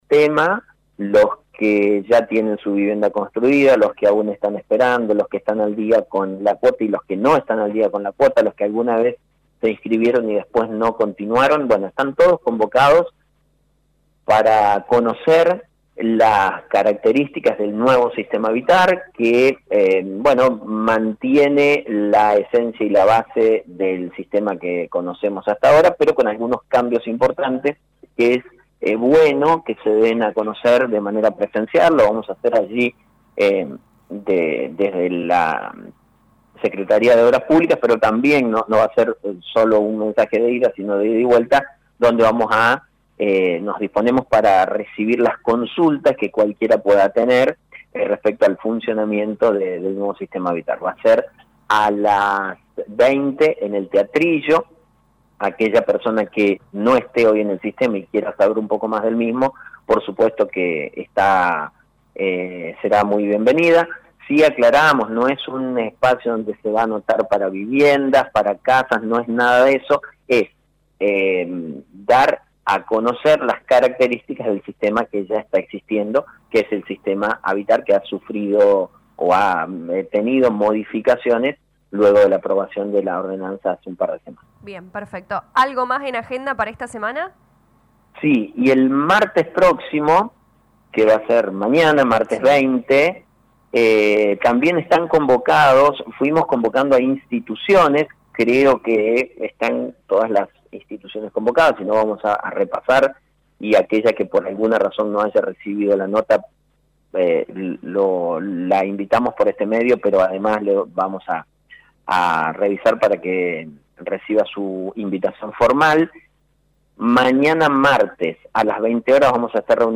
Lo anunció el intendente Municipal Lic. Mauricio Actis en diálogo con LA RADIO 102.9 FM.